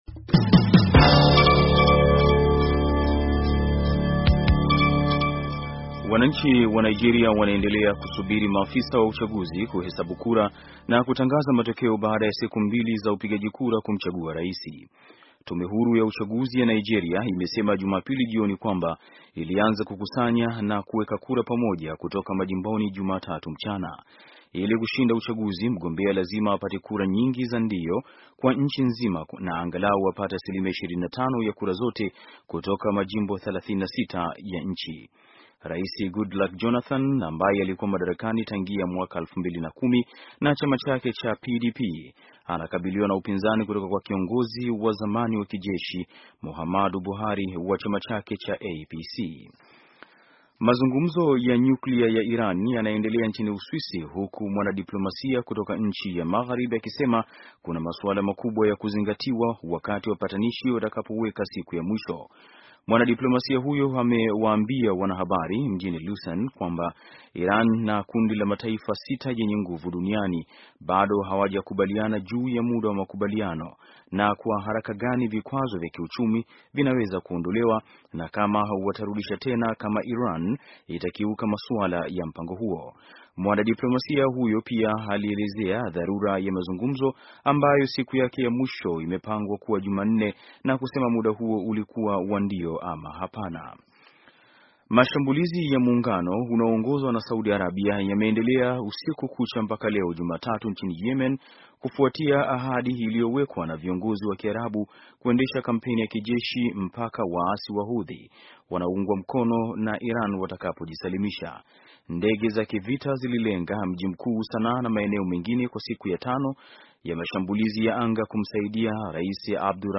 Taarifa ya habari - 4:08